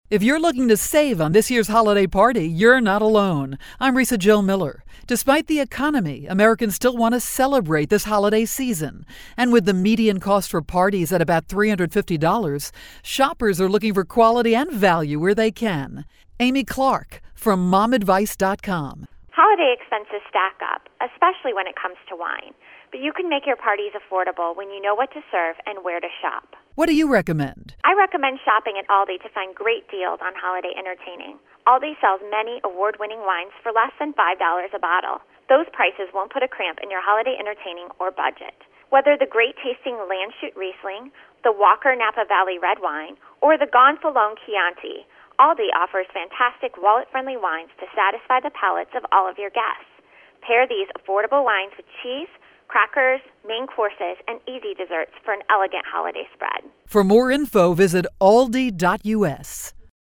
December 5, 2011Posted in: Audio News Release